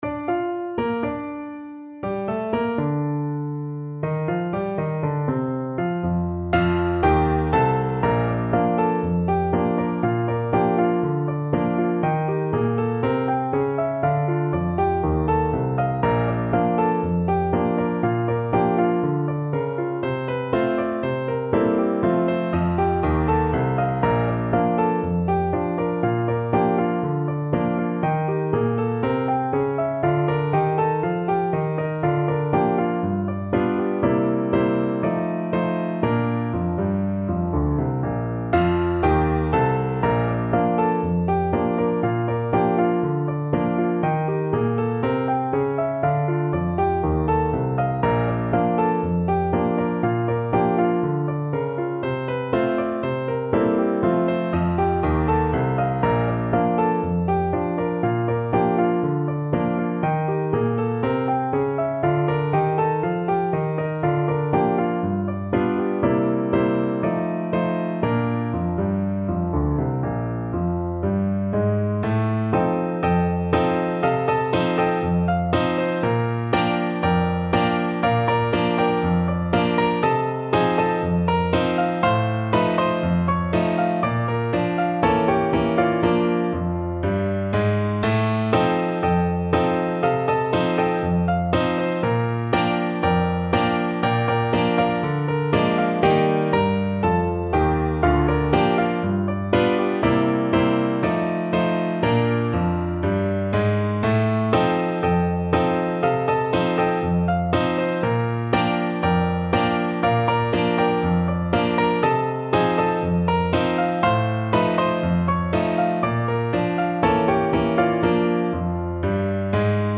No parts available for this pieces as it is for solo piano.
2/4 (View more 2/4 Music)
Bb major (Sounding Pitch) (View more Bb major Music for Piano )
~ = 60 Not fast INTRO
Piano  (View more Advanced Piano Music)
Classical (View more Classical Piano Music)